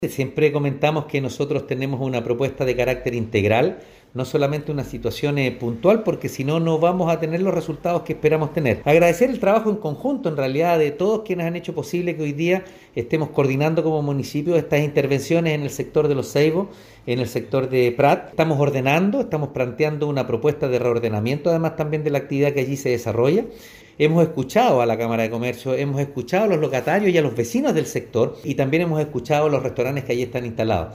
Alcalde-Oscar-Calderon-Sanchez-3.mp3